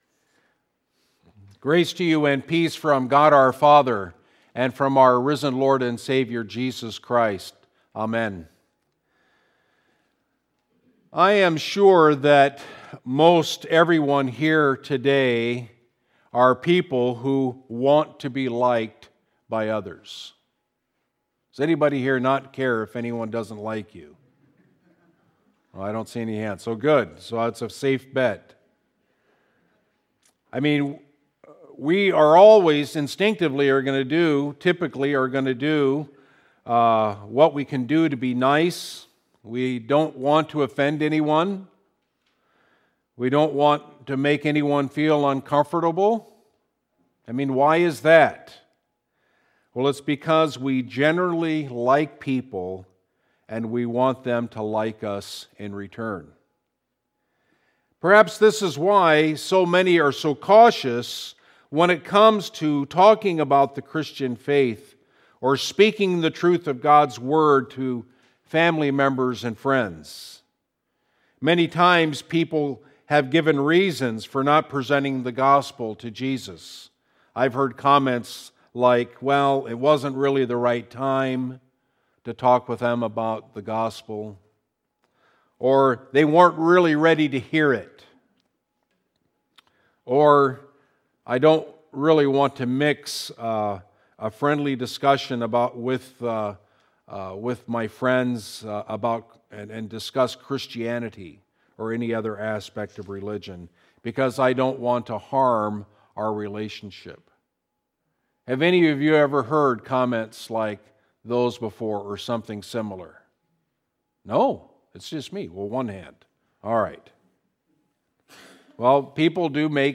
Sermon delivered 17 February 2019 at Christ Lutheran Church of Chippewa Falls, WI Text: St. Luke 6:17-26.